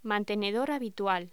Locución: Mantenedor habitual
voz
Sonidos: Hostelería